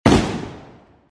Звуки дробовиков
Один выстрел